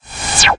Silence.ogg